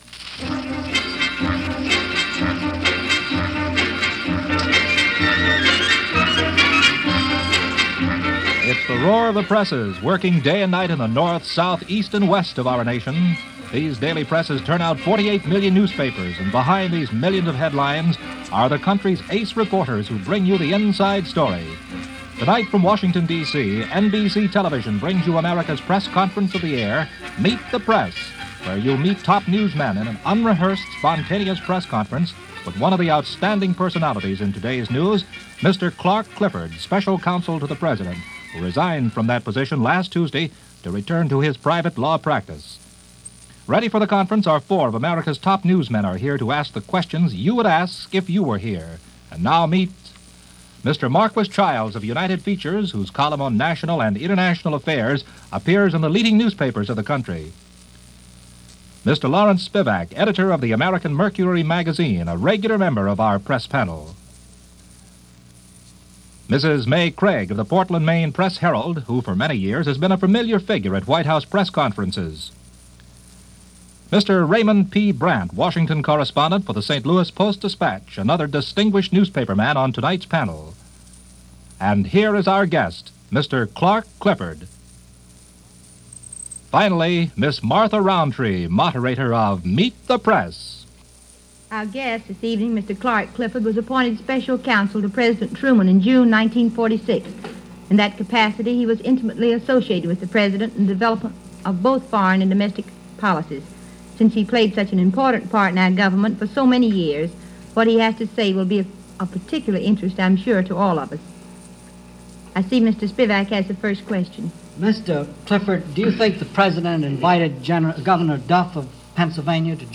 Former White House Council Clark Clifford is interviewed on this episode of Meet The Press from February 4, 1950. Having announced his departure from the Truman White House and a return to private law practice, Clifford is grilled on a variety of topics – from the upcoming mid-term elections, to the Presidential election in 1952, to the current status in the Cold War and our position among nations in the world during what was largely considered an unsettling time.